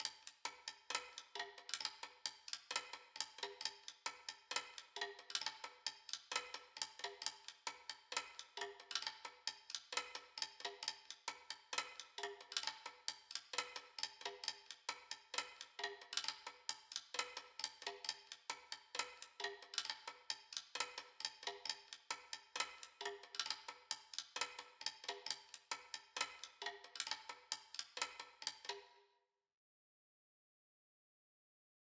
Blvd 133 African Drums.wav